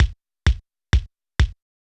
TEC Beat - Mix 14.wav